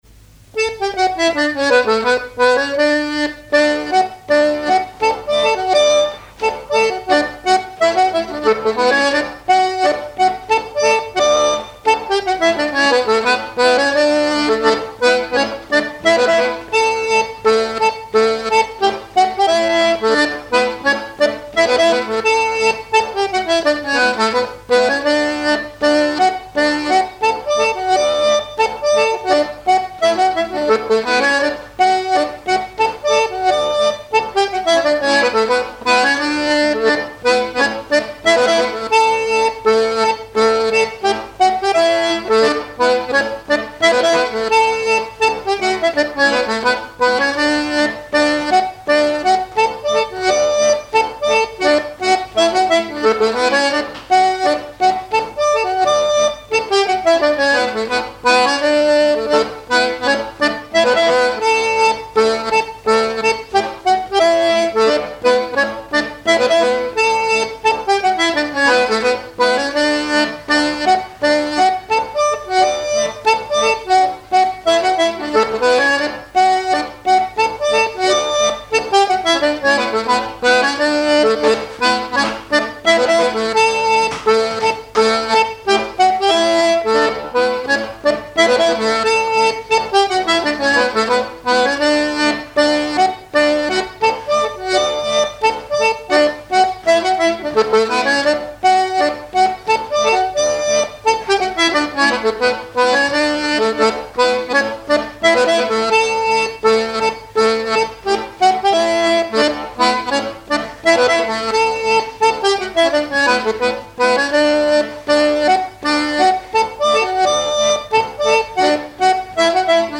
Mémoires et Patrimoines vivants - RaddO est une base de données d'archives iconographiques et sonores.
pas d'été
airs de danse à l'accordéon diatonique
Pièce musicale inédite